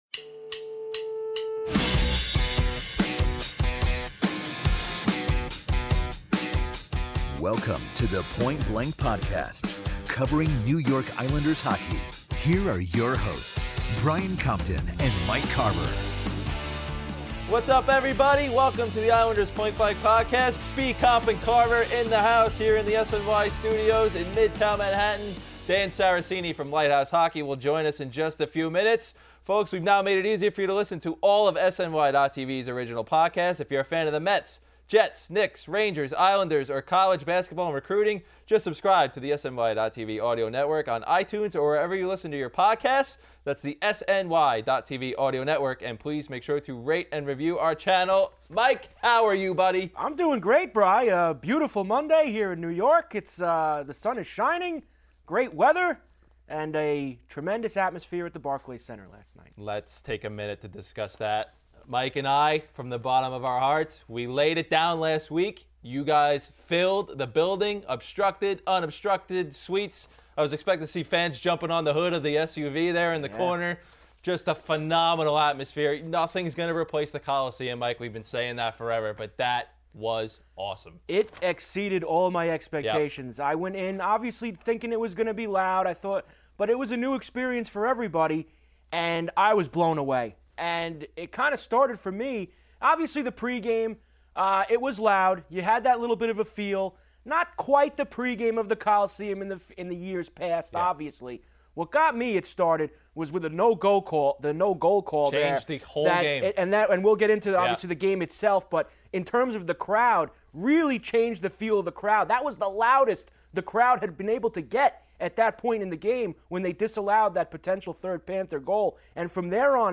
Plus, a very special guest enters the studio to discuss his now famous Game 3 prediction, and what he thinks the final score will be in Game 4.